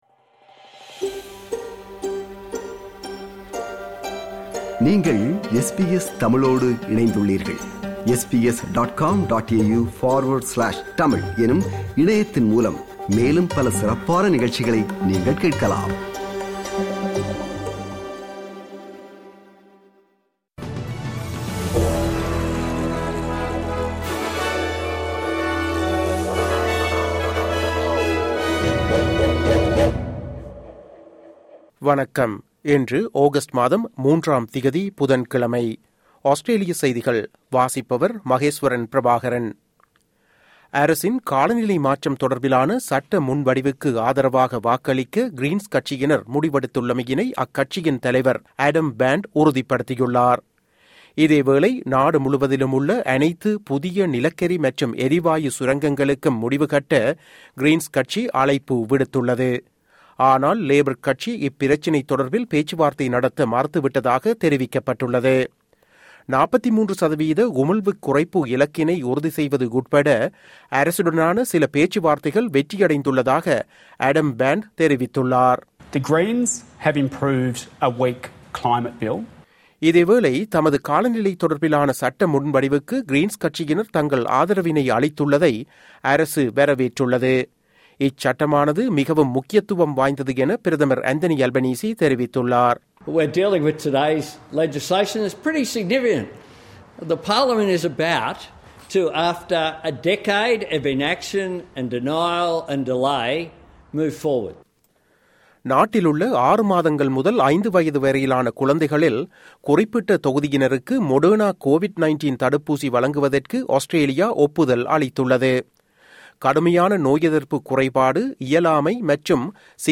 Australian news bulletin for Wednesday 03 August 2022.